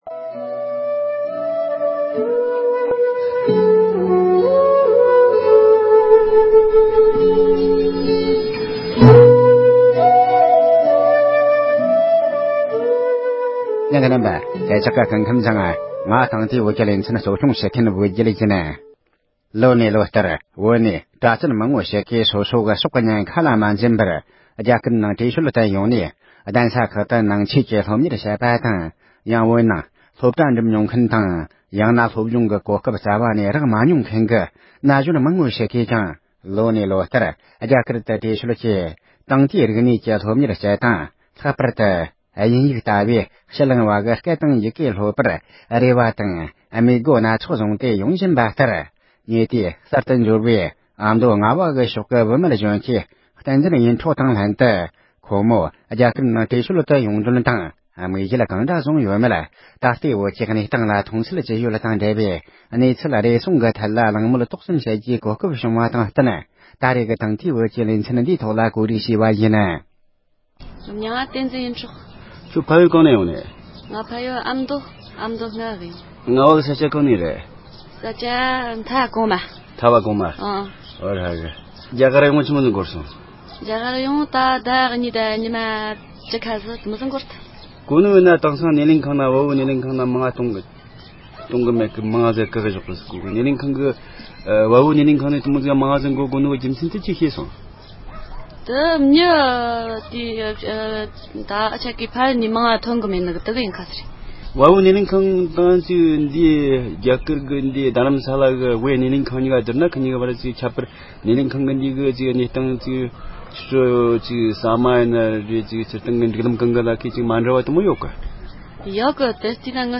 སྒྲ་ལྡན་གསར་འགྱུར། སྒྲ་ཕབ་ལེན།
གླེང་མོལ